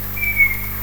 Canto di uccello notturno ignoto , Natura Mediterraneo | Forum Naturalistico
Nell'estate del 2102 di notte in una via della mia città ho registrato con il cellulare la voce di un uccello che, stando sopra i rami più alti dei pini, emetteva una specie di fischio lamentoso ad intermittenza regolare. Il livello sonoro era abbastanza elevato ed al momento ho pensato ad un Assiolo con qualche problema di "identità" però l'ipotesi non reggeva in quanto gli altri esemplari, presenti a poca distanza, gli rispondevano con lo stesso tono ed allo stesso modo e per me non erano Assioli con il classico verso chiù.
Purtroppo è un unico suono altrimenti non lo carica perché file troppo pesante.
Questo verso veniva ripetuto in continuazione a distanza di un paio di secondi anche dagli altri.
Pullus di Gufo comune
Se può essere utile il verso che veniva emesso era ad un volume elevato e continuato.